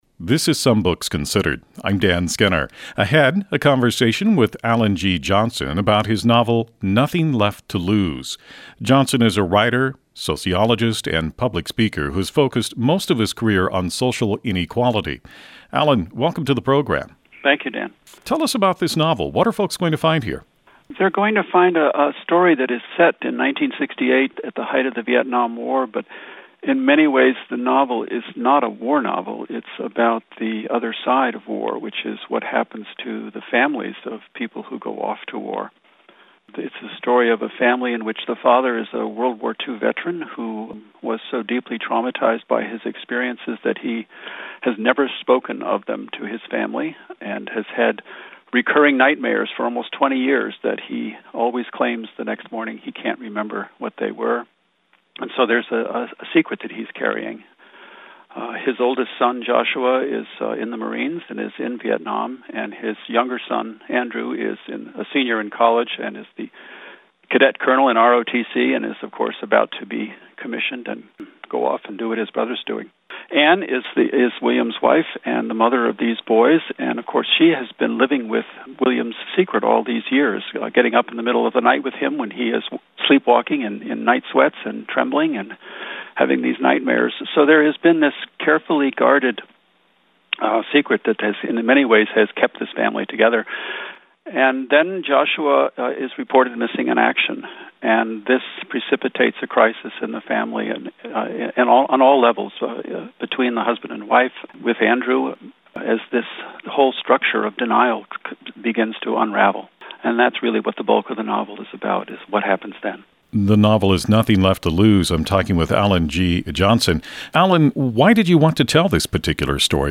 Nothing Left to Lose NPR Interview
Click on the link below to stream the MP3 interview or right click and save to download it onto your computer.